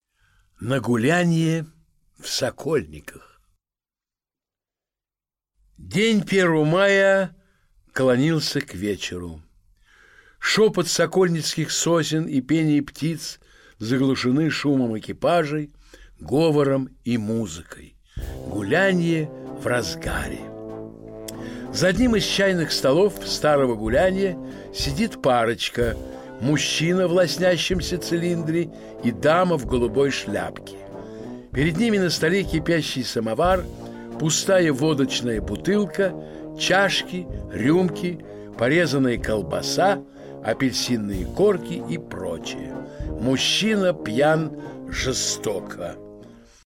Аудиокнига На гулянье в Сокольниках. рассказ | Библиотека аудиокниг
Aудиокнига На гулянье в Сокольниках. рассказ Автор Антон Чехов Читает аудиокнигу Валентин Гафт.